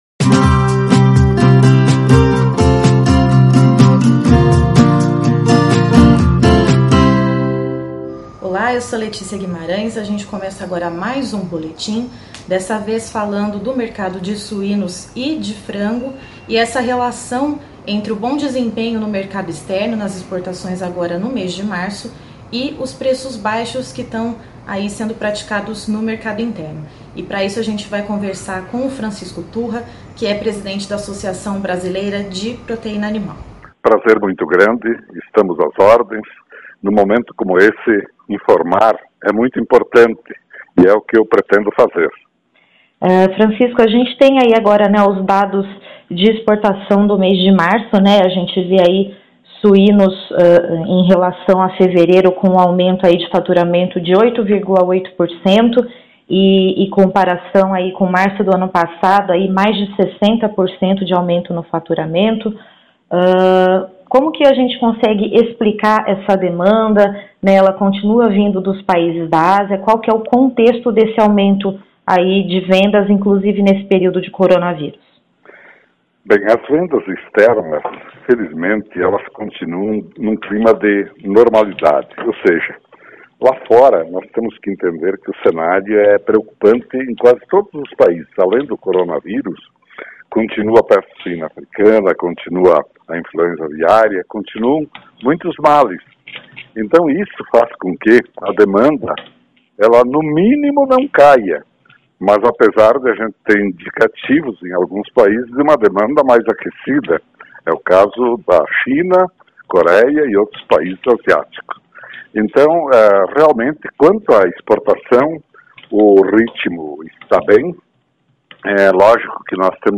Entrevista com Francisco Turra - Presidente ABPA sobre as Exportações de Suínos